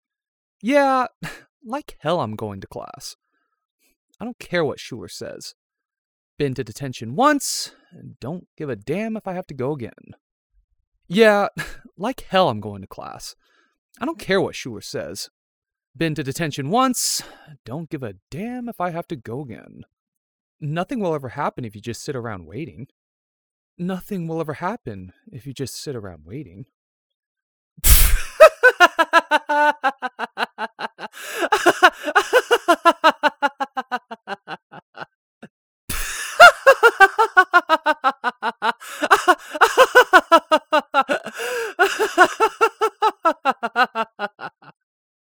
Voice: High to medium pitch, flirtatious, possibly flamboyant. He laughs a LOT.